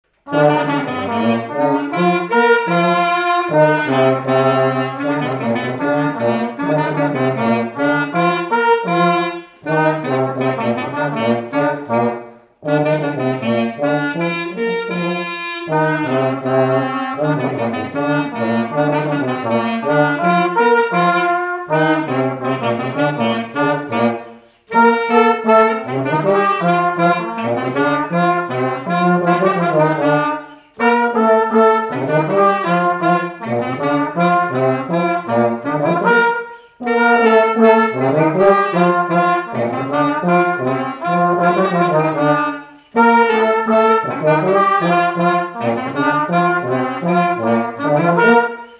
Scottish
Résumé instrumental
danse : scottish (autres)
Enquête Arexcpo en Vendée
Pièce musicale inédite